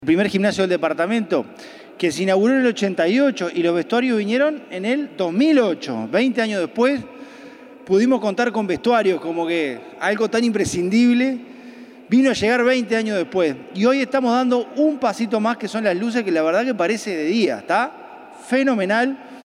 francisco_legnani_secretario_general_1.mp3